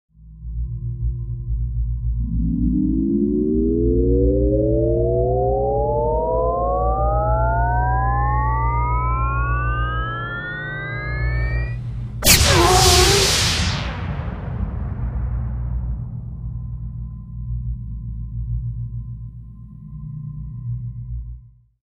Зарядка лазерной пушки, прицеливание, выстрел